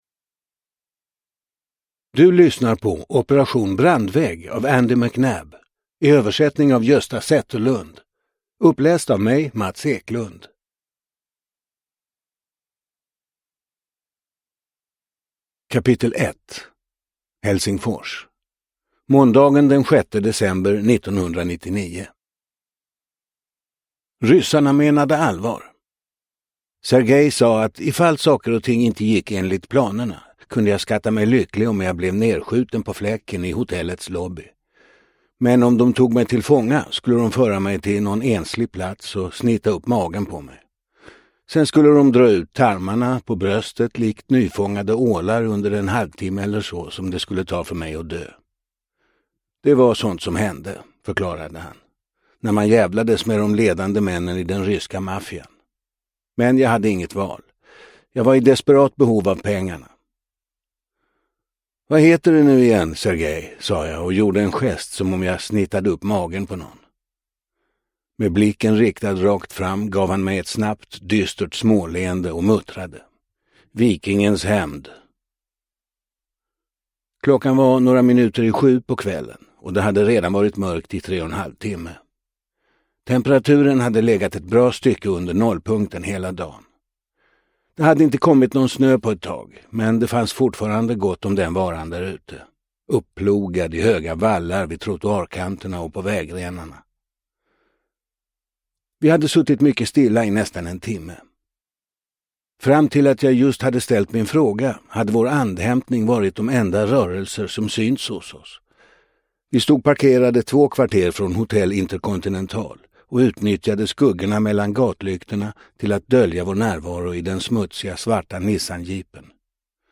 Operation: Brandvägg – Ljudbok – Laddas ner